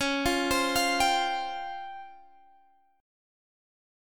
Listen to C#M7b5 strummed